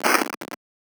scavengers_footstep1.aif